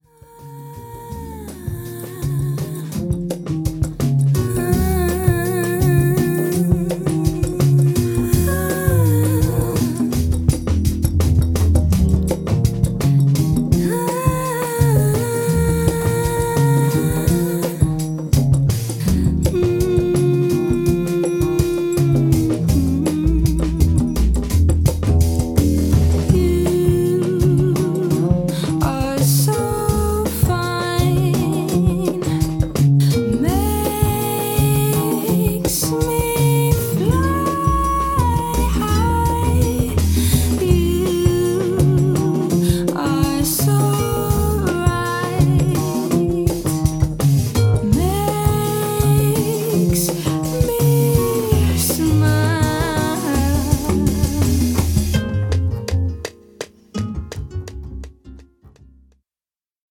ホーム ｜ JAZZ / JAZZ FUNK / FUSION > JAZZ